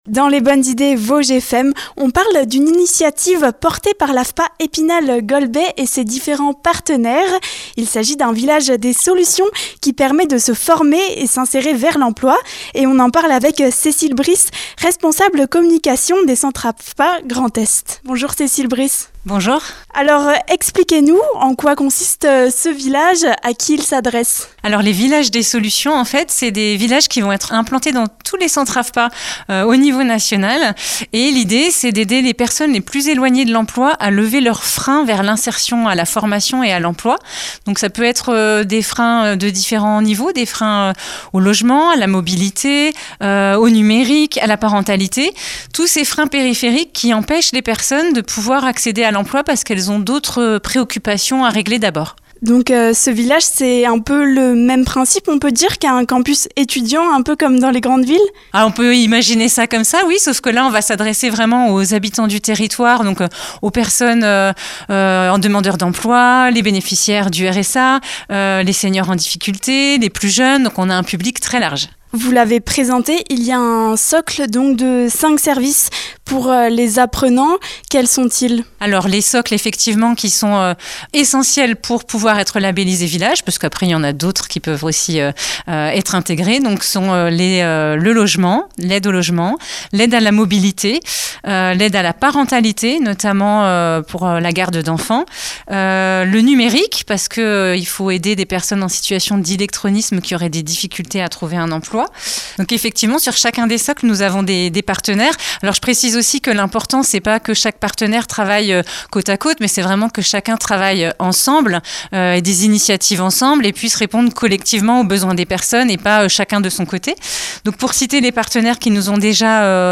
C'était le cas fin de semaine dernière notamment, à l'occasion de l'inauguration du Village des Solutions au centre AFPA d'Epinal/Golbey. Un lieu où les stagiaires qui souhaitent suivre une formation pourront trouver de nombreuses solutions pour vivre au mieux leur expérience.